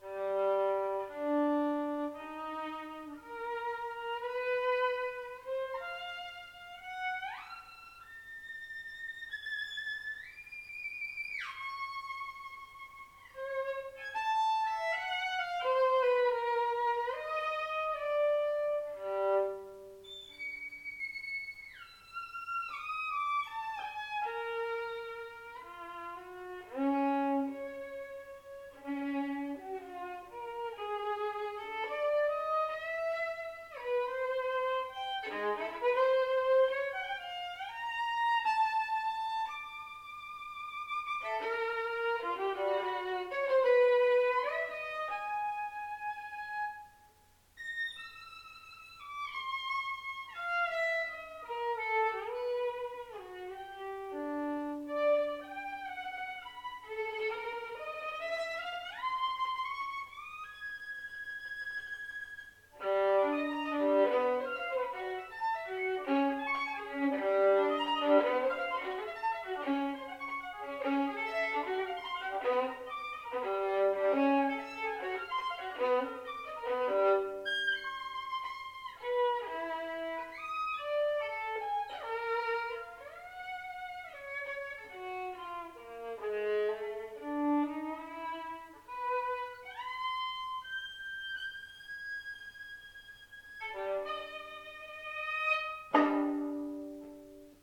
Per Violino solo